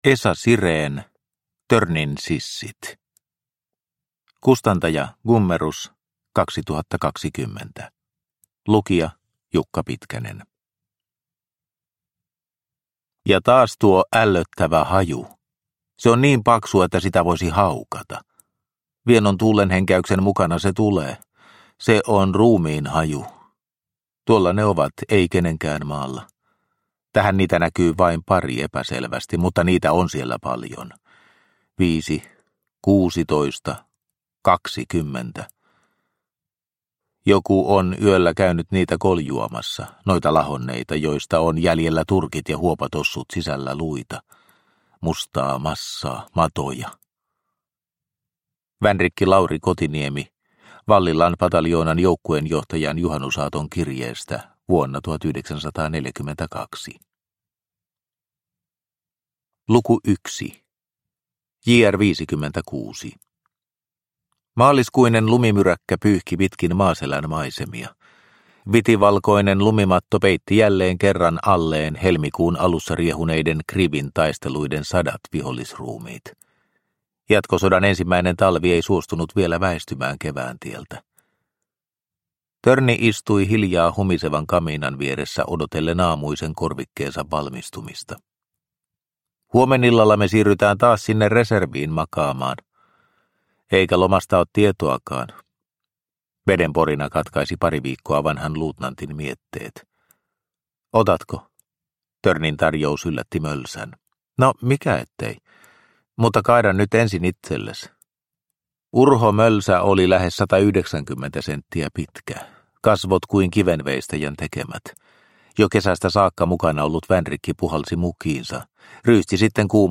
Törnin sissit – Ljudbok – Laddas ner